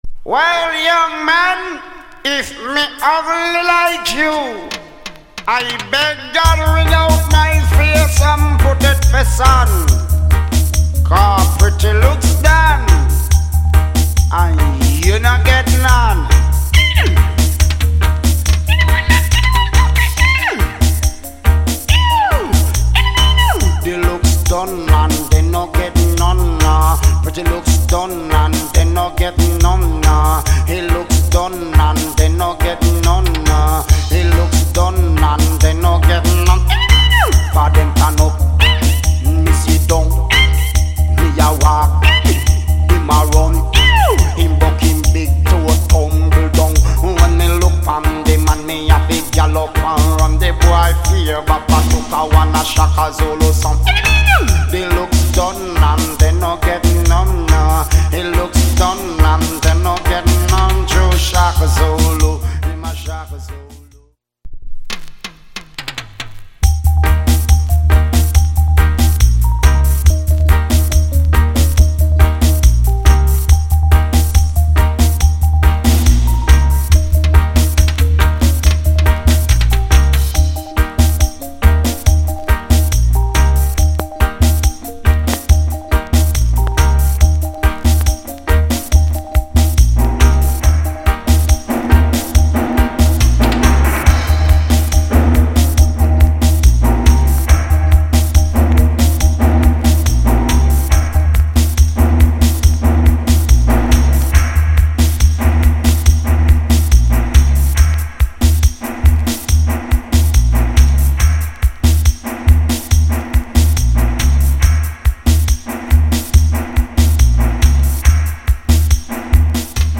奇声Big Hit Tune!!